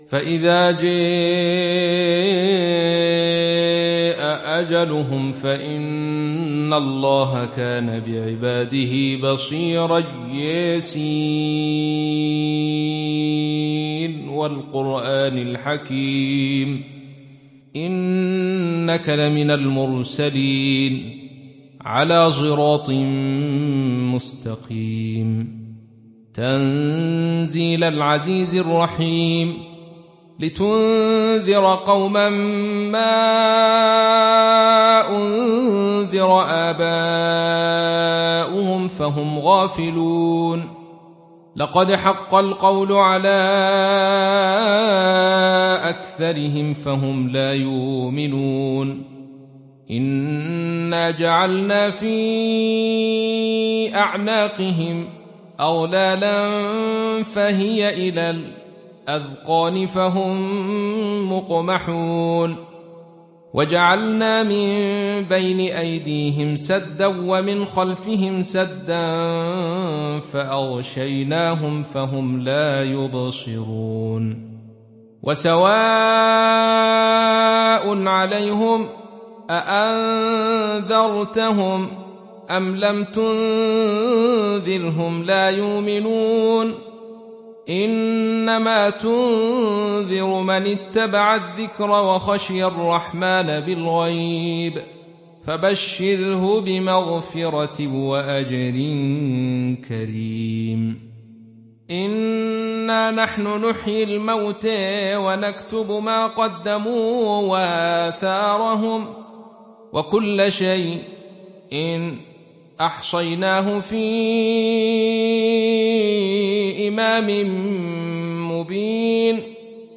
Surat Yasin Download mp3 Abdul Rashid Sufi Riwayat Khalaf dari Hamza, Download Quran dan mendengarkan mp3 tautan langsung penuh